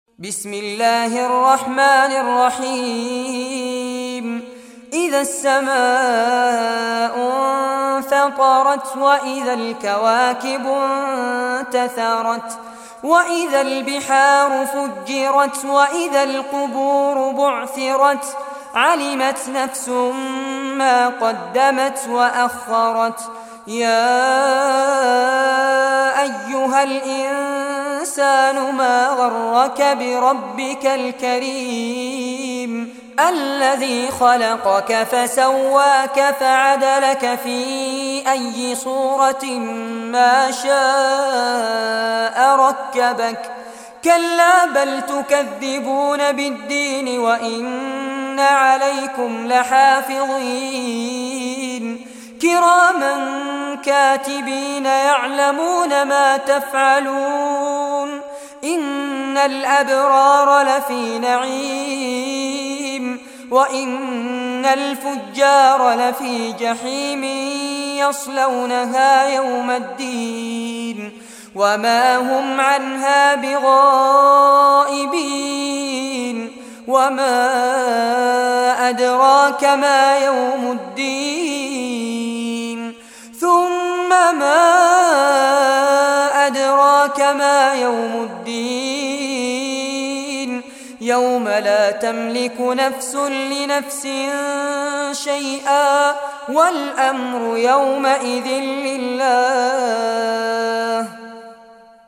recitation in Arabic in the beautiful voice